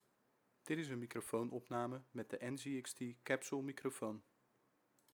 Het geluid klinkt zeer helder. De microfoon is uitgerust met een 25 mm condensatorcapsule.
Wat mij vooral opvalt met de Capsule is de realistische vertoning van het stemgeluid.
Hier op het kantoor, is de Capsule met zijn cardioide geluidskarakteristiek wel wat gevoeliger voor geluid van de omgeving.
Hieronder vind je een geluidsfragment van de NZXT Capsule.
De opnamekwaliteit is consistent en helder voor spraak.